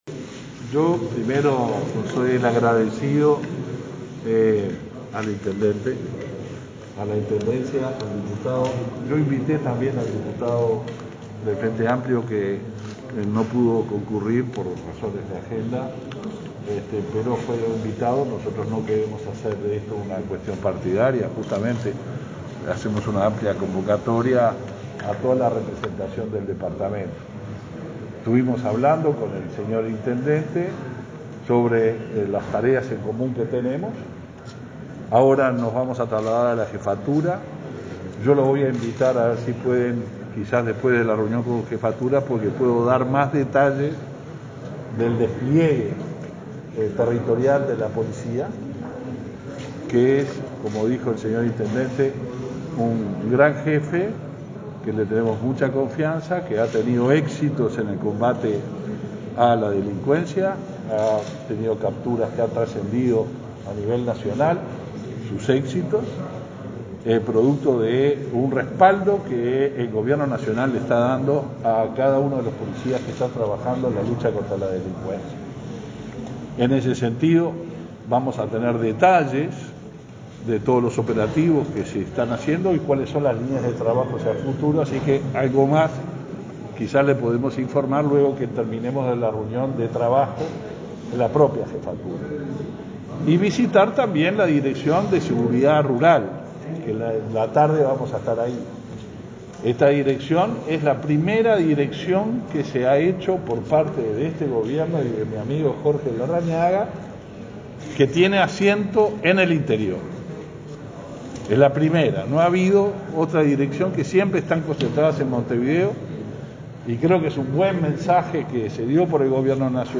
Declaraciones del ministro del Interior, Luis Alberto Heber
El ministro Heber brindó declaraciones a la prensa durante su visita al departamento de Florida.